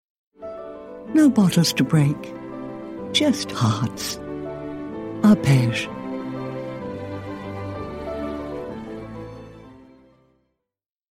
Female
English (British)
Am a professional voice actor with a unique sound - relaxed yet formal, playful yet serious.
Corporate
Arpege - Smooth Midatlantic